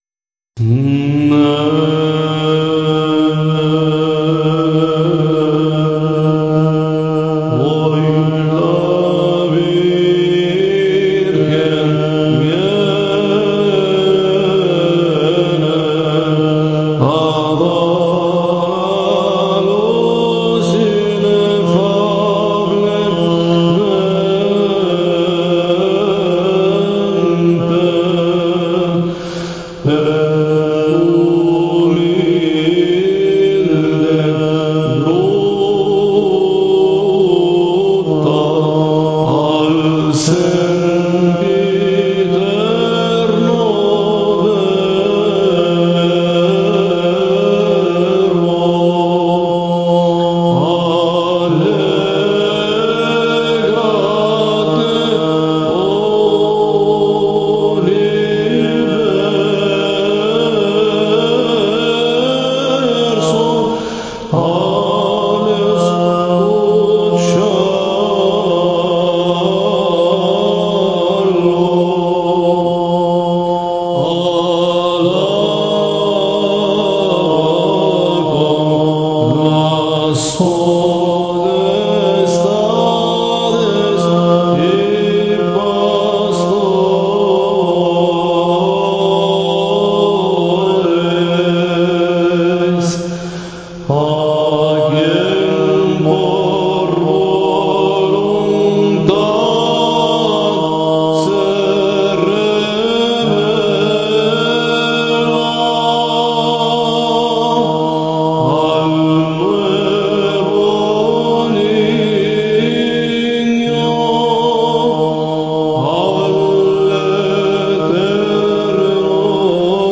Himnos de la Liturgia
Tono 3